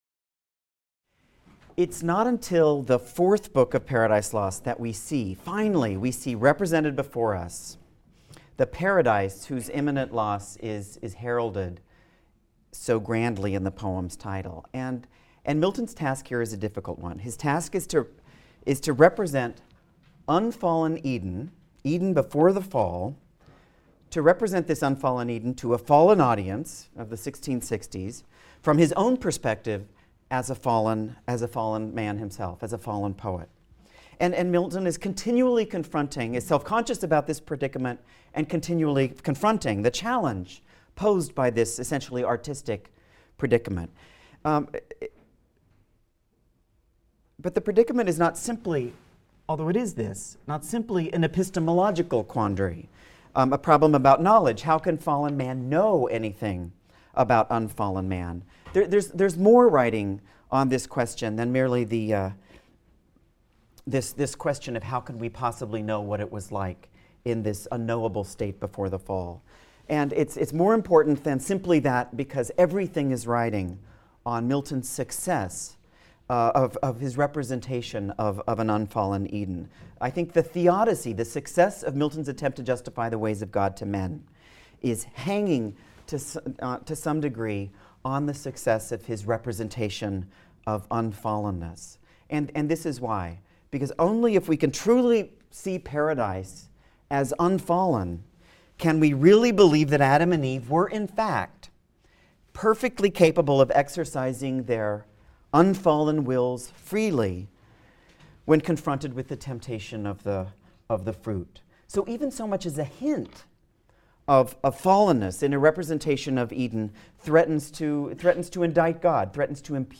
ENGL 220 - Lecture 14 - Paradise Lost, Book IV | Open Yale Courses